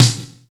62 SNARE 2.wav